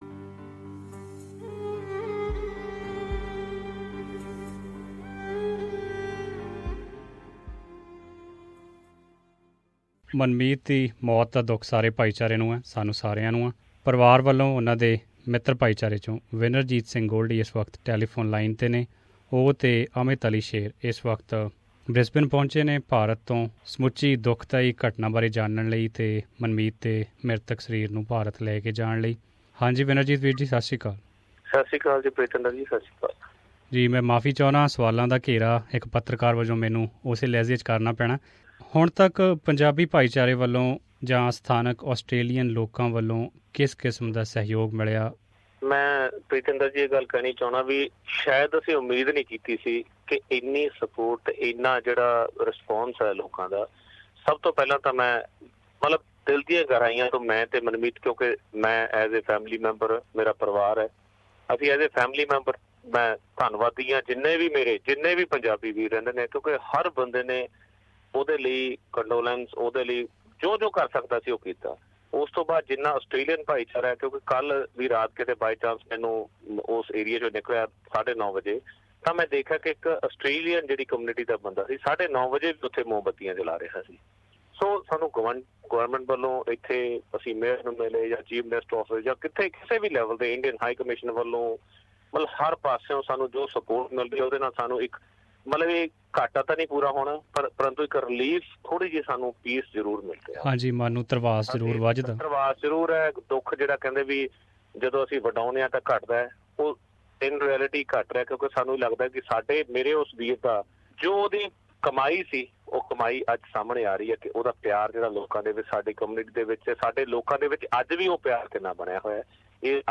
LISTEN TO THE FULL INTERVIEW WITH SBS RADIO (IN PUNJABI) ABOVE He said Indian High Commissioner